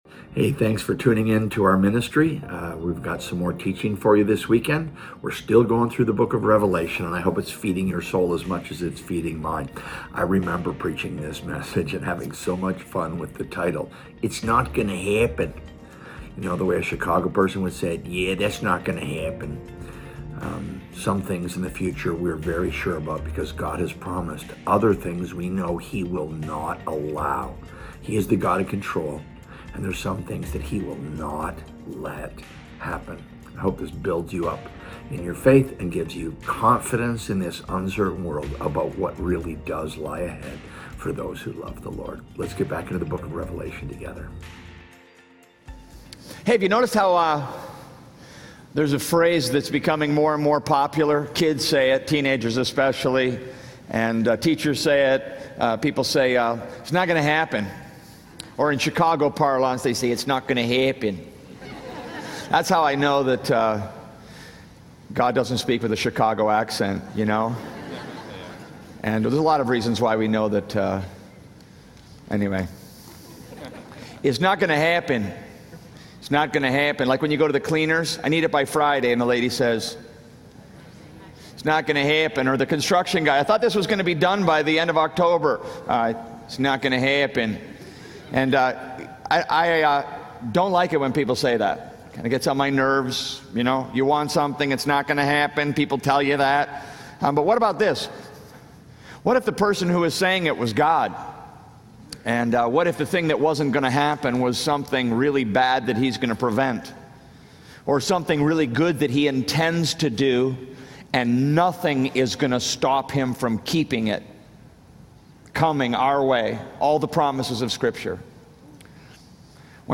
preaches without apology straight from the pages of Scripture, provoking Christians to think and act on their faith.